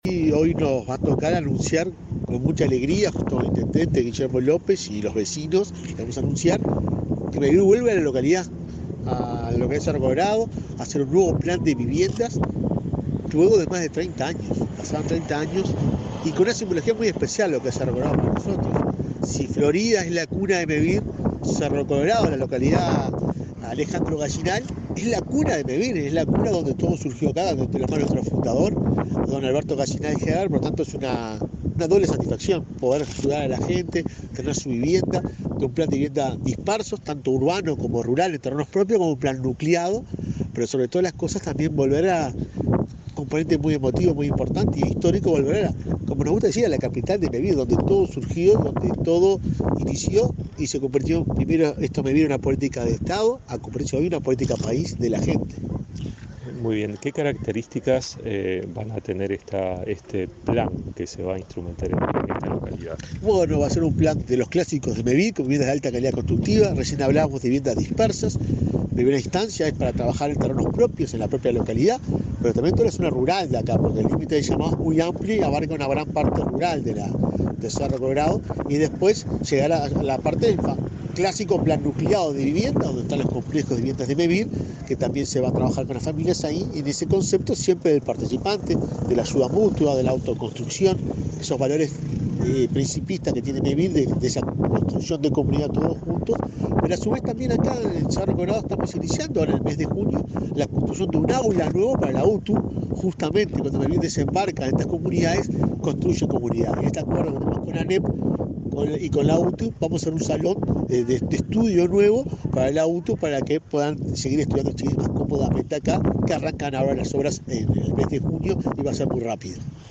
Entrevista al presidente de Mevir, Juan Pablo Delgado